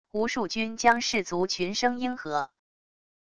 无数军将士卒群声应和wav音频